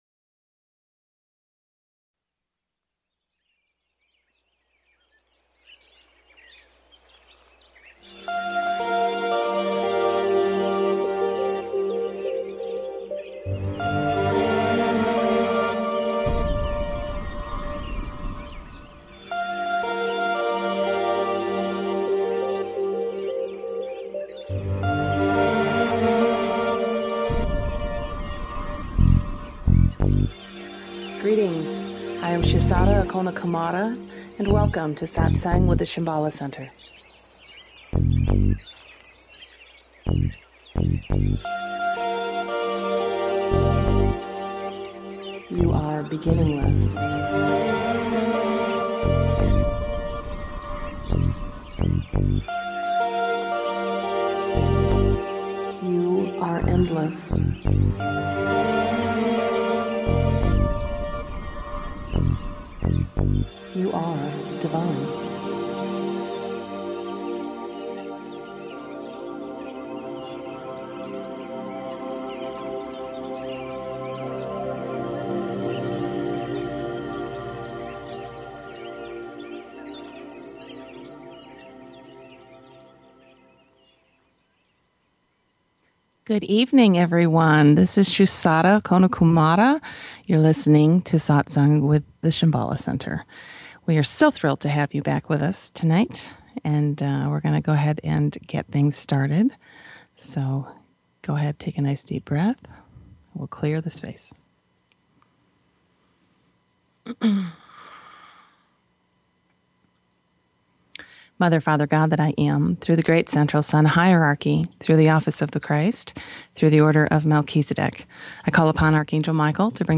Talk Show Episode, Audio Podcast
The guide facilitates the session by holding the energy for the group, providing teachings, answering questions and facilitating meditations.